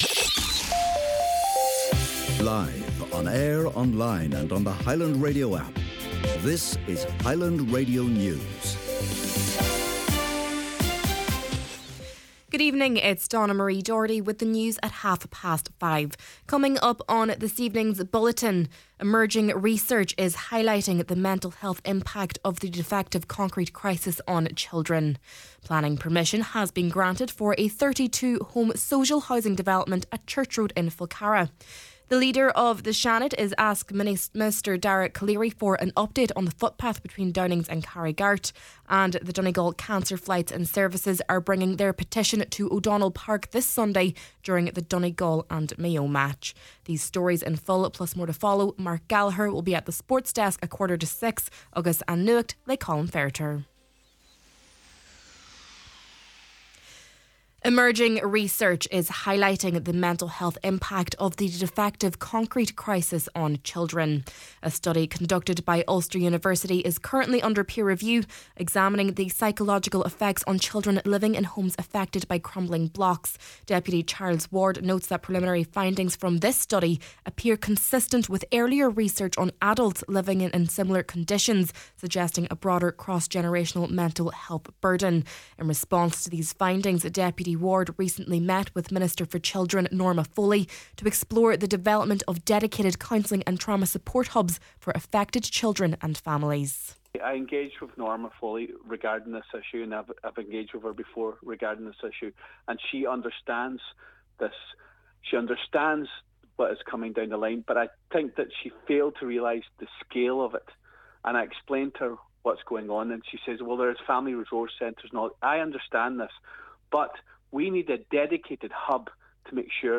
Main Evening News, Sport, An Nuacht and Obituary Notices – Friday, February 13th